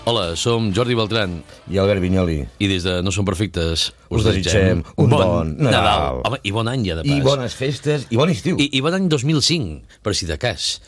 Felicitació nadalenca del programa